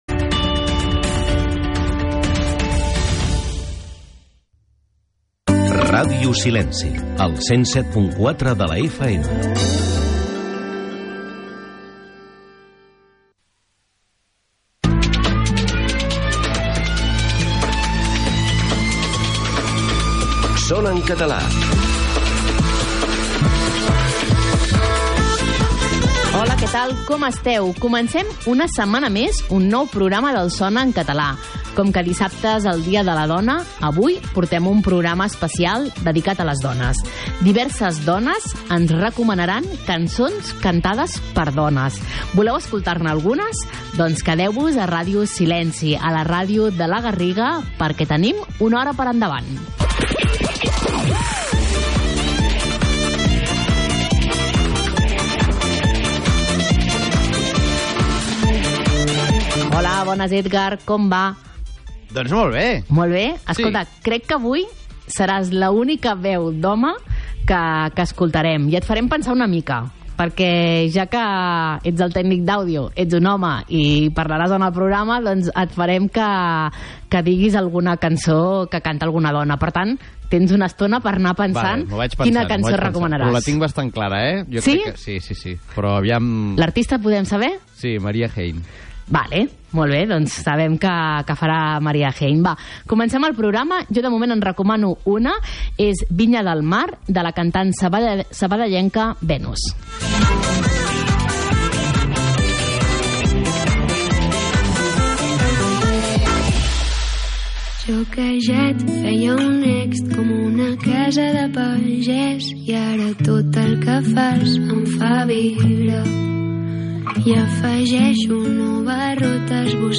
Una hora de música en català amb cançons de tots els temps i estils. Història, actualitat, cròniques de concerts, curiositats, reportatges, entrevistes...